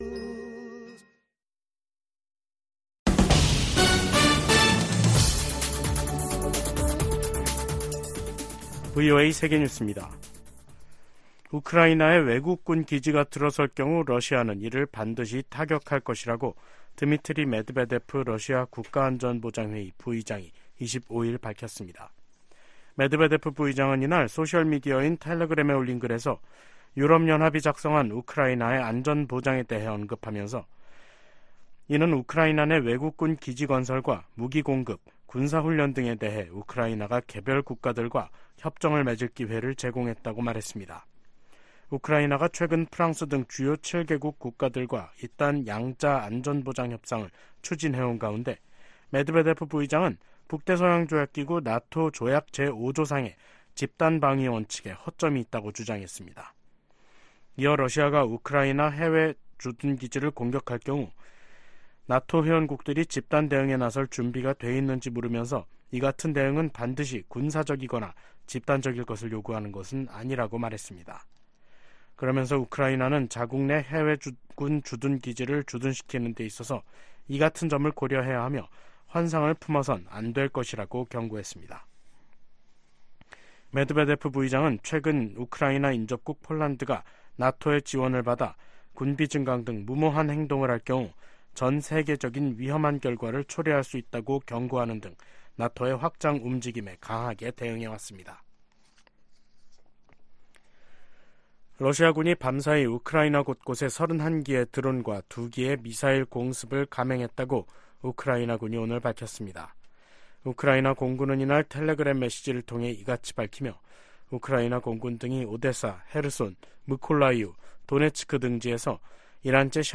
VOA 한국어 간판 뉴스 프로그램 '뉴스 투데이', 2023년 12월 25일 3부 방송입니다. 북한이 이번 주 노동당 전원회의를 개최할 것으로 보입니다. 전문가들은 이번 전원회의에서 핵 무력 강화를 지속하겠다는 강경 메시지를 내놓을 가능성이 크다고 보고 있습니다. 미국 국무부가 북한 영변 핵시설의 경수로 가동 정황에 심각한 우려를 표명했습니다.